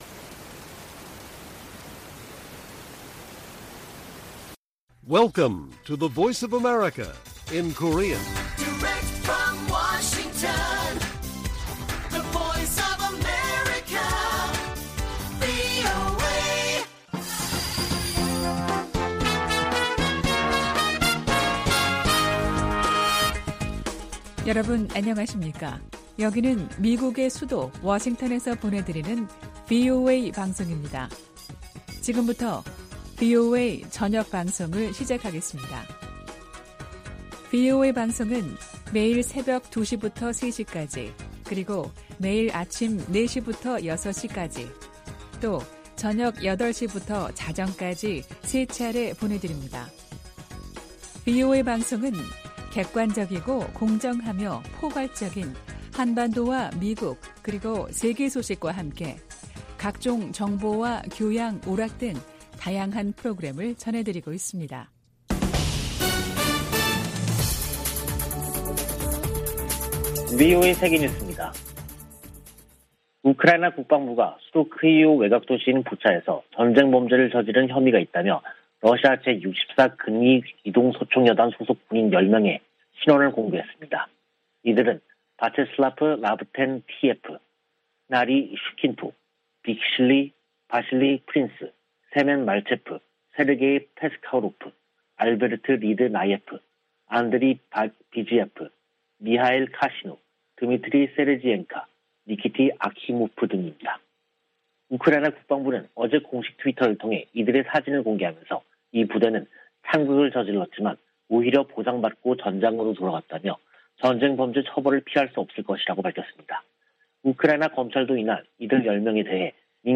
VOA 한국어 간판 뉴스 프로그램 '뉴스 투데이', 2022년 4월 29일 1부 방송입니다. 다음 달 미한 정상회담에서 동맹강화와 대북공조 등이 중점 논의될 것으로 보입니다. 김정은 북한 국무위원장의 ‘핵무력 강화’ 발언에 대해 미국은 핵 억지력과 미사일 방어망을 강화해야 한다고 상원 외교위원회 공화당 간사가 강조했습니다. 북한 문제에 대한 중국의 협력을 기대하기 어렵다고 전 국무부 동아시아태평양 차관보가 밝혔습니다.